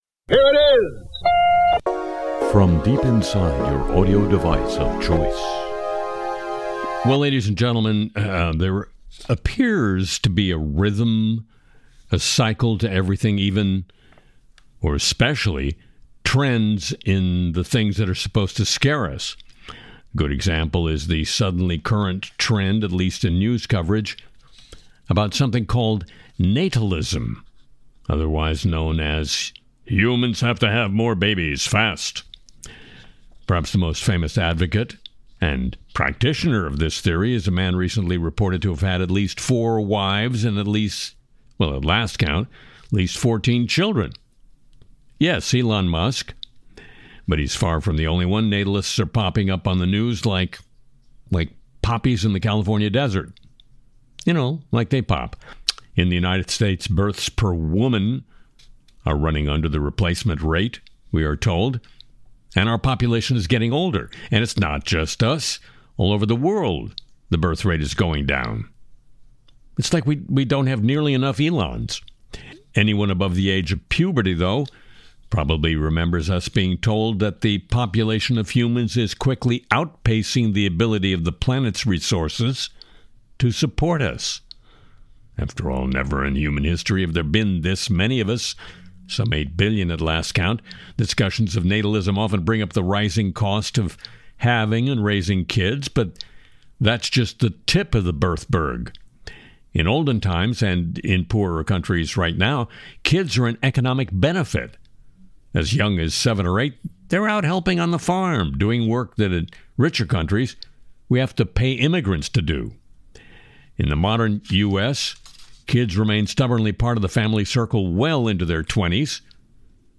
a satirical look at the news presented by American satirist Harry Shearer.
Genres : News , talk